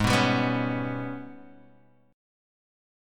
AbmM7bb5 chord